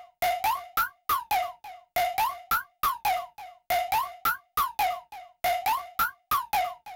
FM GROOVE -R.wav